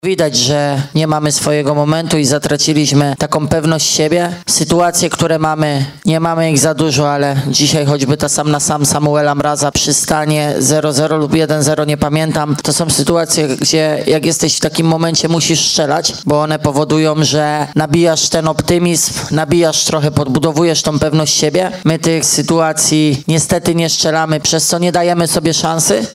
mówił na konferencji pomeczowej